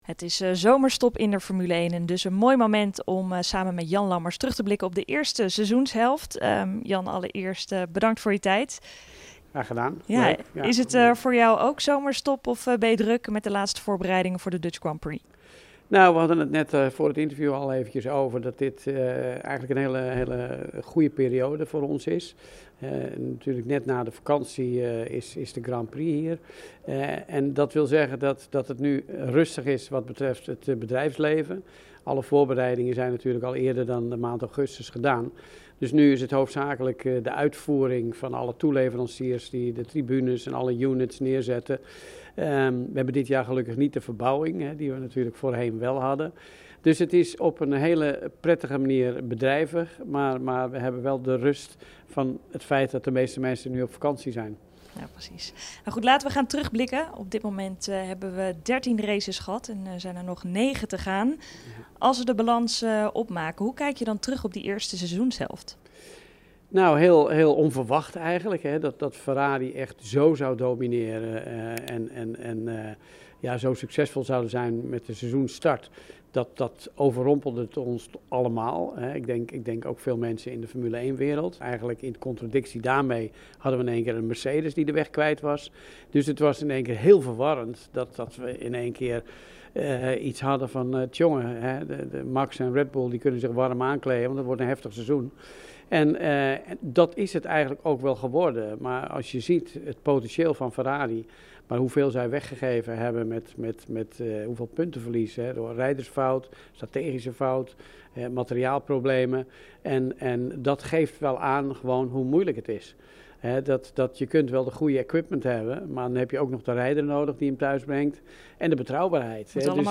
De zomerstop in de Formule 1 is een mooi moment om even bij te praten met voormalig F1-coureur Jan Lammers.